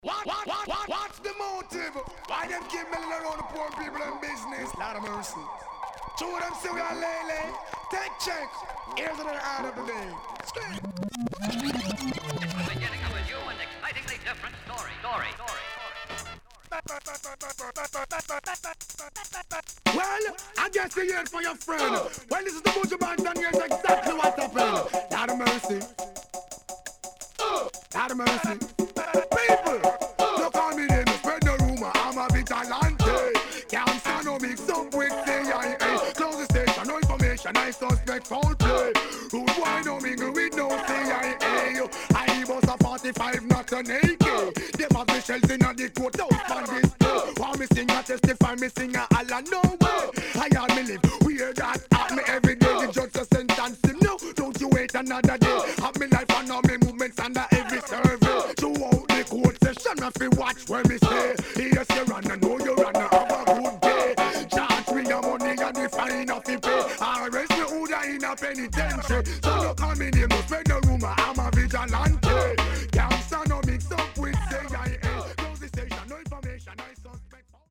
勢いある打ち込みオケのNice Dancehall